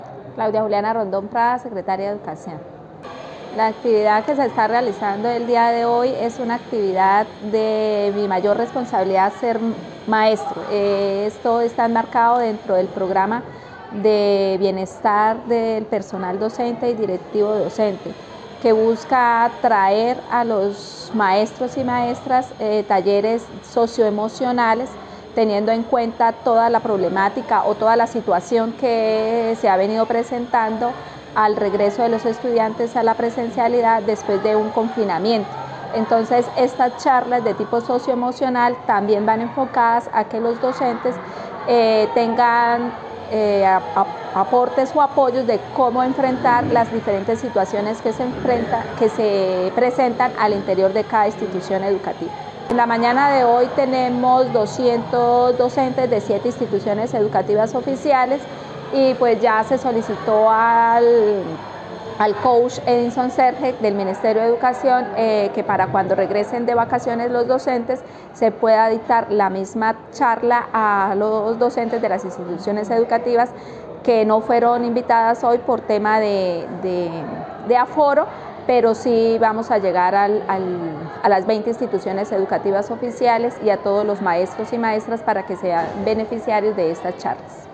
Claudia Juliana Rondón - Secretaria de Educación.mp3